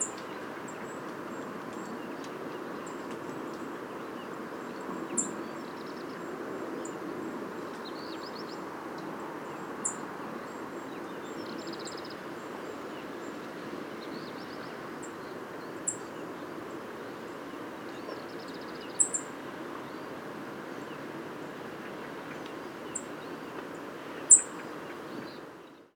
Luckely I was able to make a few sound recordings of bird flight
101008, Song Thrush Turdus philomelos, call in flight, Altenfeld, Germany